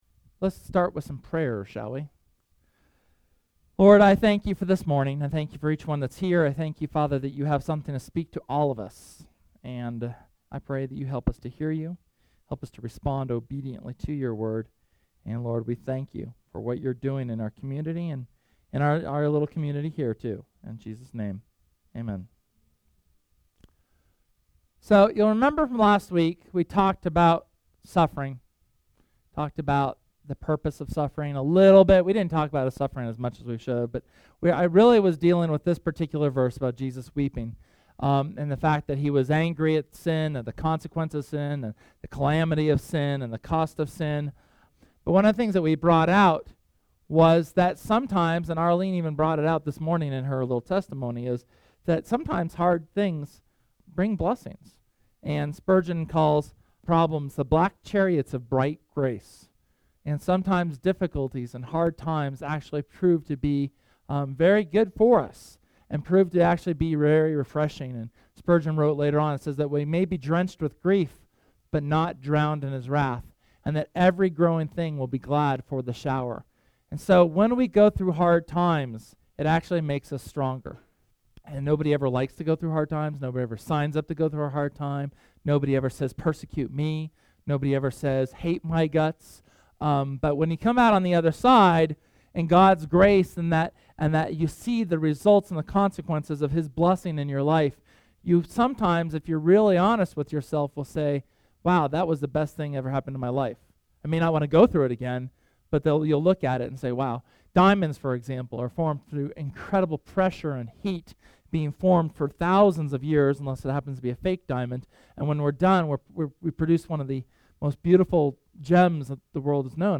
SERMON: Use both hands